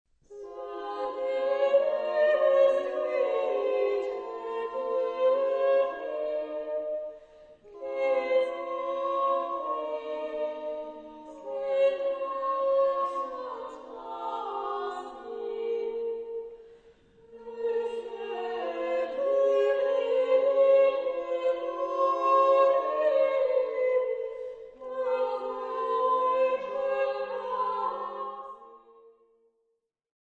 E' una composizione vocale , generalmente a tre voci , così definita: alla base c'è il tenor (la voce principale), che esegue un cantus firmus ; al tenor si associano due voci, chiamate motetus (la seconda) e triplum (la terza), che cantano ovviamente melodie differenti. La particolarità di questa forma sta però nel fatto che motetus e triplum , oltre alle note, cantano anche testi differenti .
L'audio presenta un breve frammento di un mottetto tratto dai Manoscritti di Montpellier (XIII secolo).
mottetto.mp3